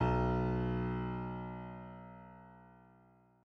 piano-sounds-dev
SoftPiano